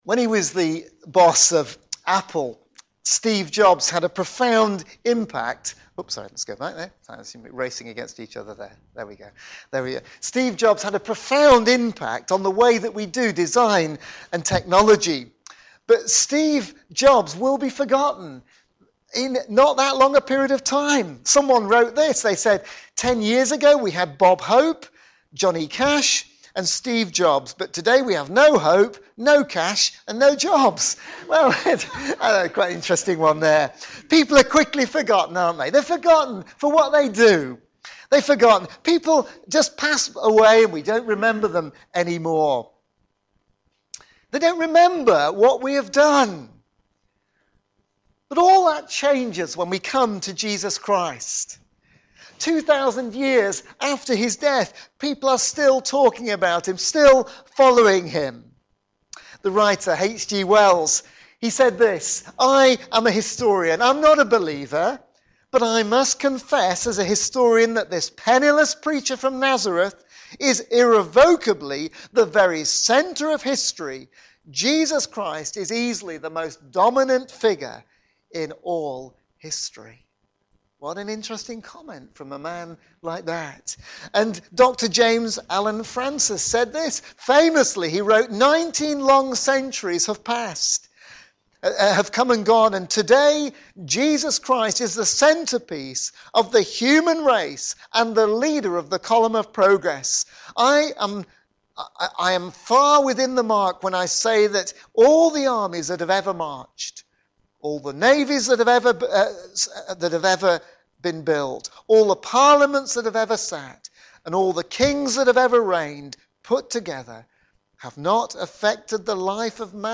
Media Library Media for a.m. Service on Sun 23rd Oct 2016 10:30 Speaker
Theme: Great Saviour - Great Salvation Sermon